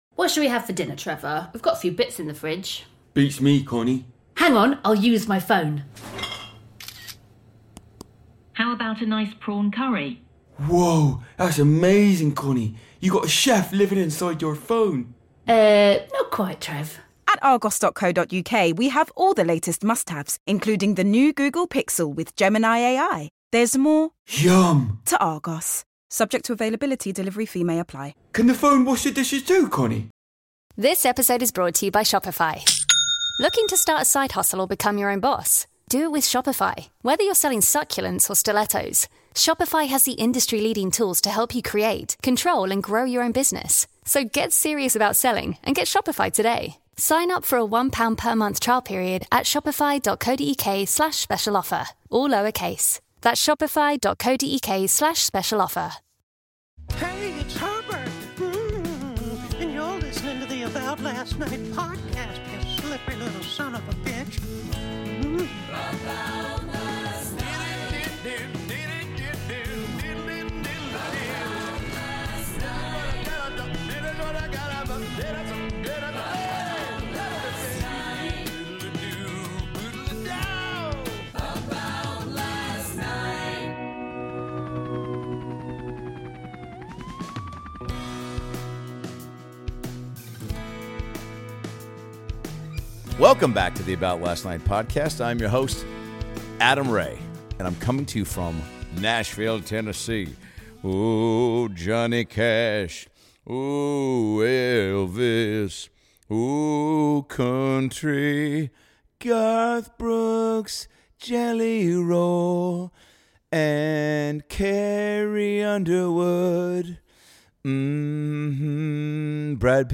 #779 - Adam Ray Records from Nashville and Talks about Weird Names for Hurricanes & Kamala Harris and Donald Trump talking McDonalds
Adam Ray is back and recoding a new About Last Night from Nashville! Adam talks about the weird names for hurricanes, dealing with strange people for customer service and when Kamala Harris and Donald Trump face off at McDonald's of all places!